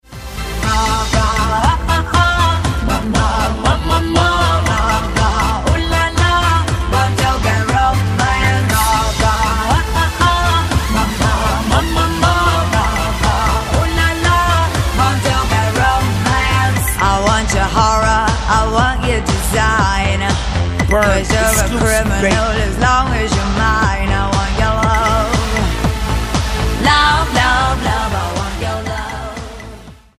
американская певица